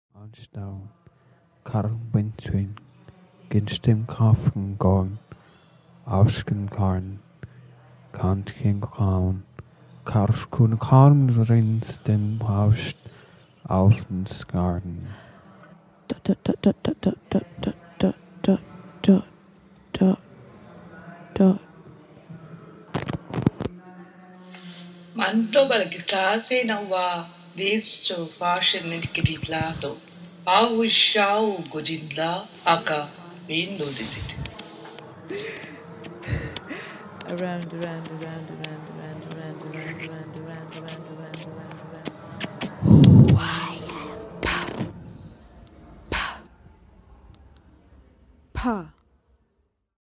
People were invited to read non-sense characters from artist’s poetry book and their anonymous voices were collected to create an abstract body that claimed repressed emotional voices and a potential moment of lingual anarchy, a language of the subconscious, a morphed body from a visual to a sonic form.
Never to repeat and never to remember, each word was used only once with the utterances constituting the realm of unknown, of excited wonderment where expressions can be voiced sans inhibitions, presenting a hope to shake the foundations of power structures through engagement and participation.